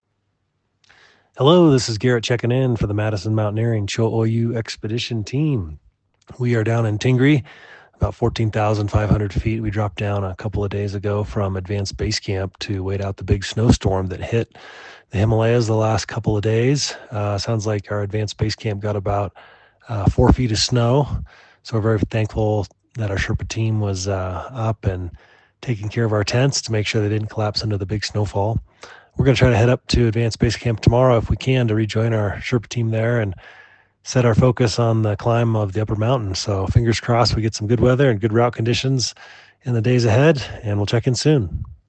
Waiting out the storm in Tingri!